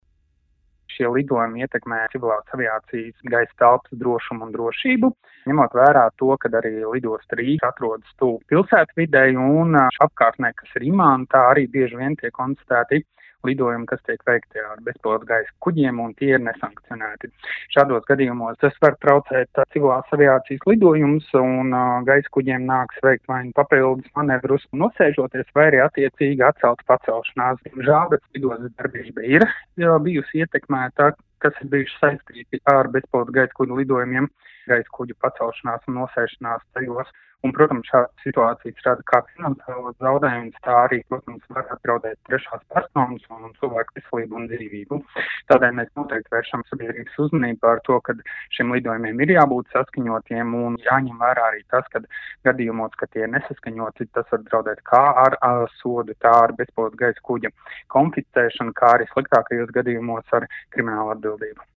RADIO SKONTO Ziņās par dronu uzraudzības iespēju palielināšanos lidostas tuvumā